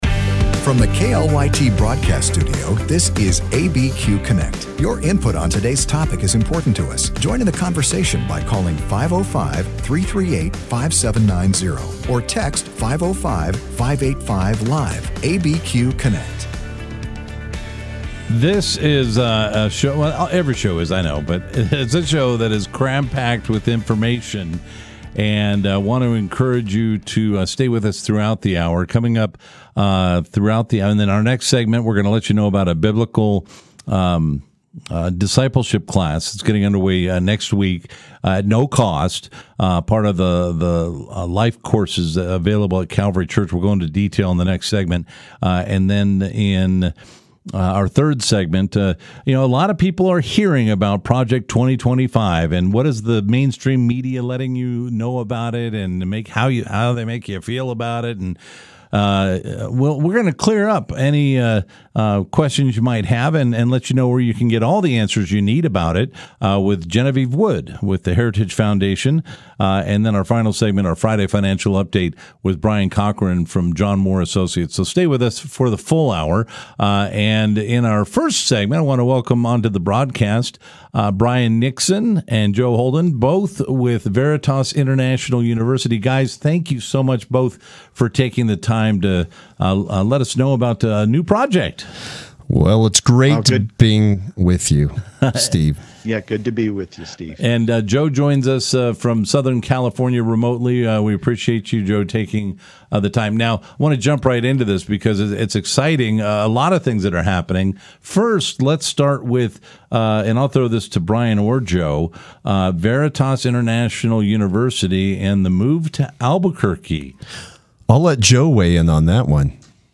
Live, local and focused on issues that affect those in the New Mexico area.